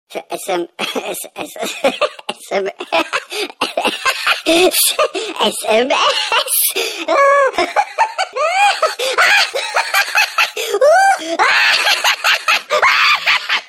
Главная » Файлы » Музыка на SMS